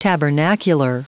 Prononciation du mot tabernacular en anglais (fichier audio)
Prononciation du mot : tabernacular